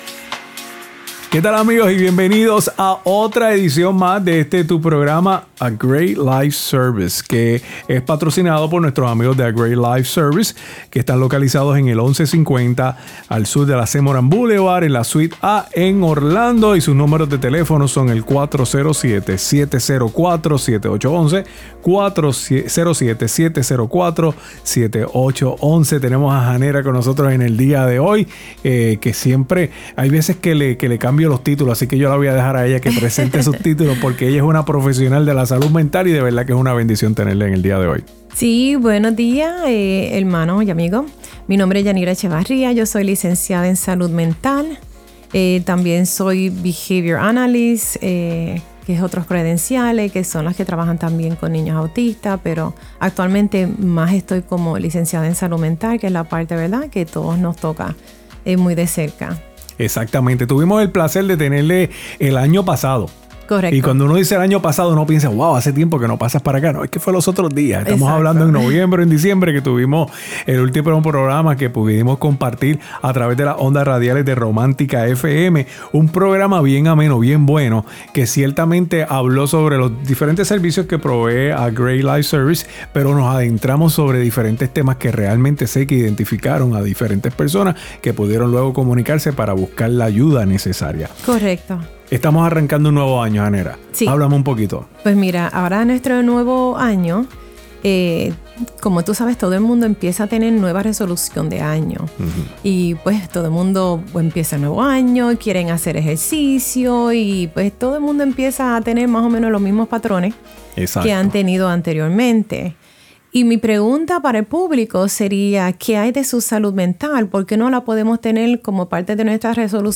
Interview on Viva FM